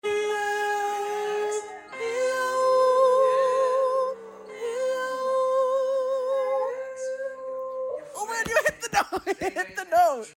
Doing Vocal Warmups With My Sound Effects Free Download